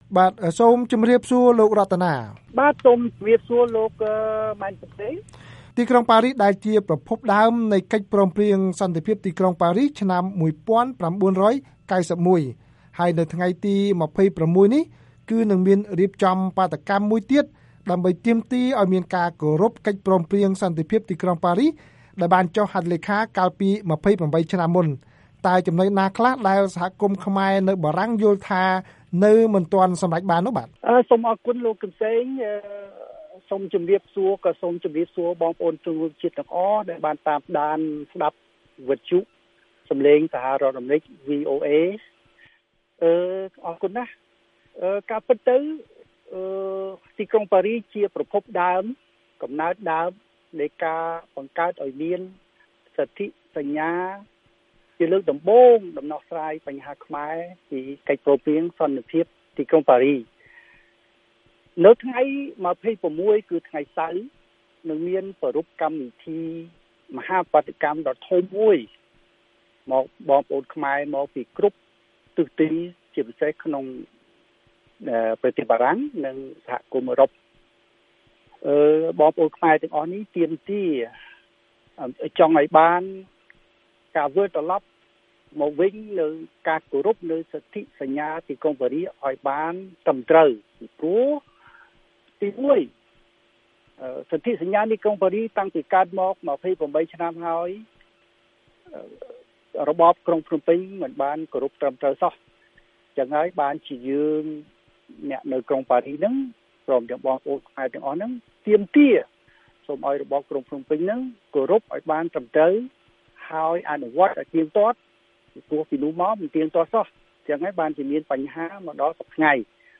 បទសម្ភាសន៍ VOA៖ ខ្មែរនៅបារំាងនឹងធ្វើបាតុកម្មរំលឹកខួបទី២៨នៃកិច្ចព្រមព្រៀងប៉ារីស